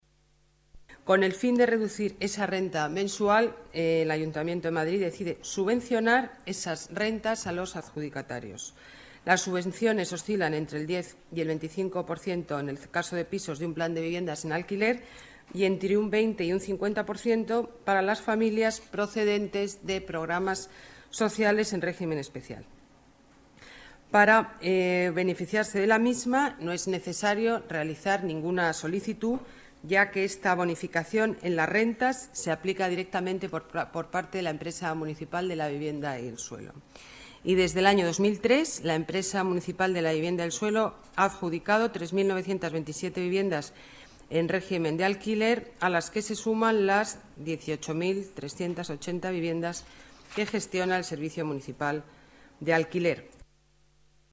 Nueva ventana:Declaraciones alcaldesa Madrid, Ana Botella: alquiler EMVS más barato